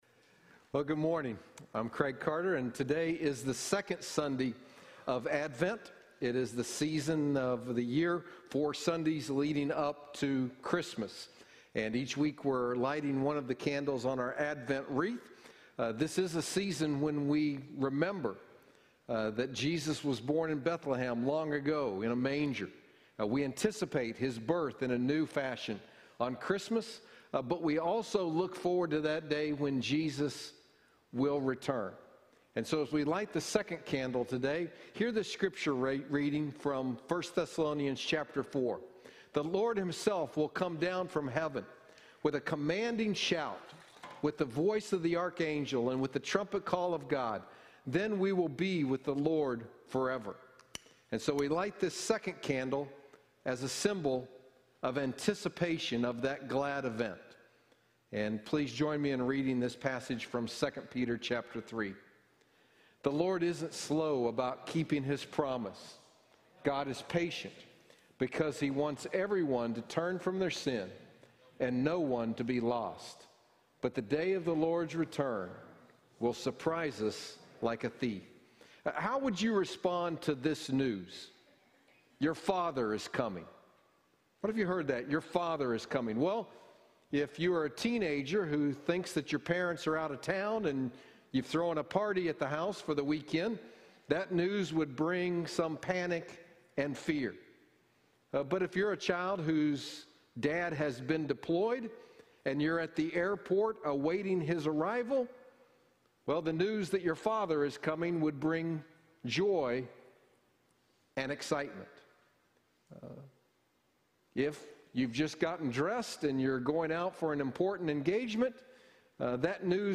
Sermon-audio-12.6.20.mp3